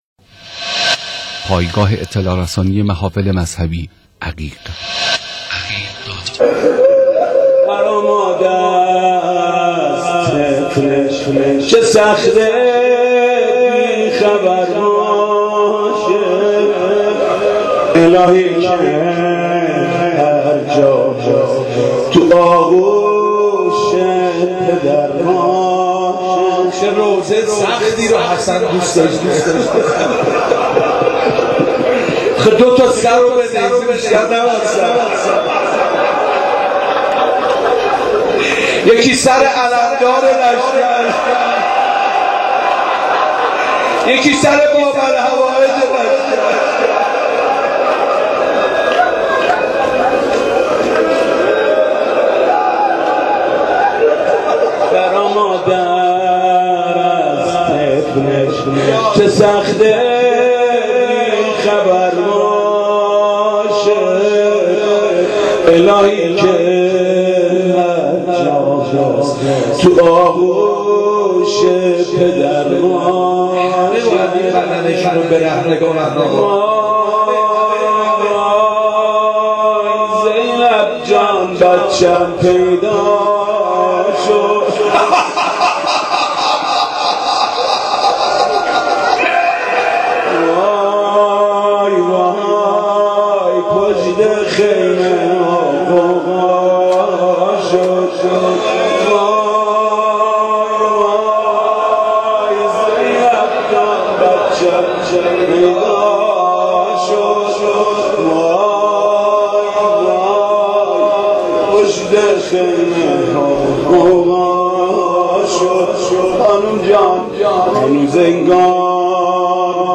مداحی حاج محمود کریمی در ویژه برنامه موج الحسین علیه السلام در سالروز شهادت شهید حسن تهرانی مقدم